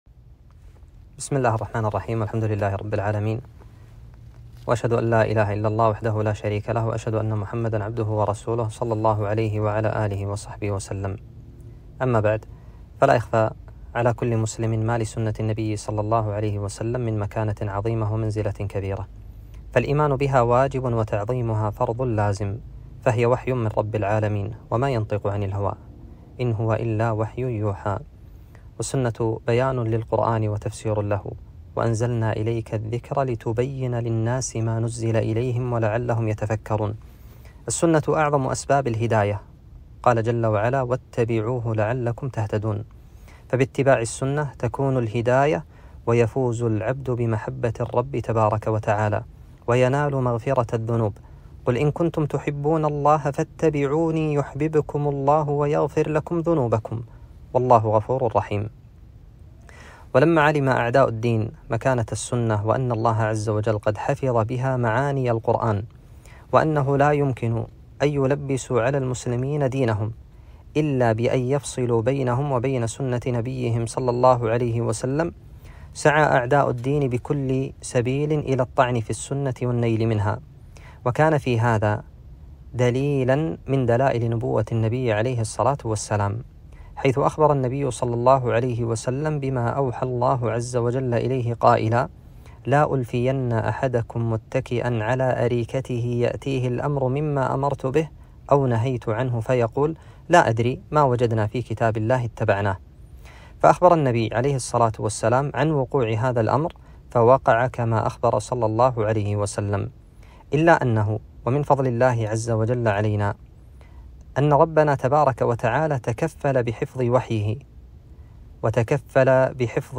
كلمة - مكانة السنة النبوية، والتعريف بكتاب: دعوى تعارض السنة مع العلم التجريبي